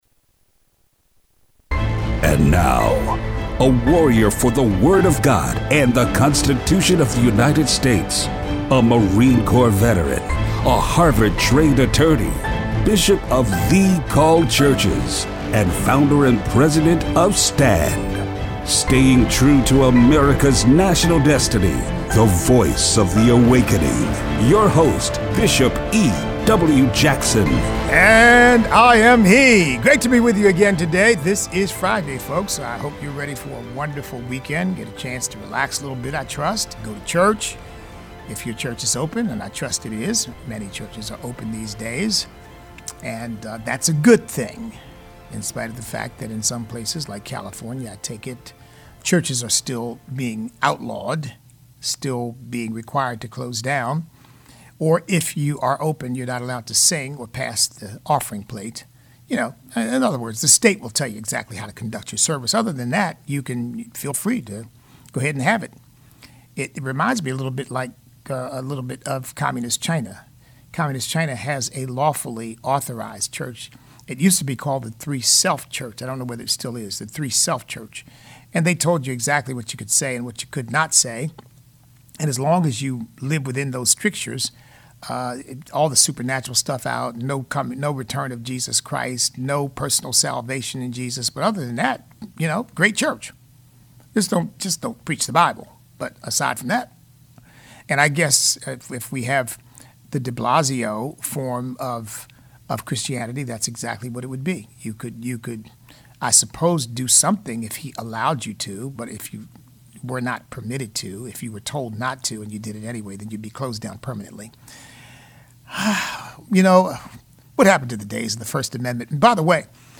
Show Notes Open phone lines this hour to let us hear what's on your mind.